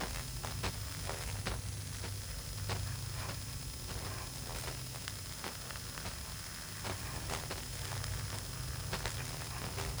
Hum Loop 6.wav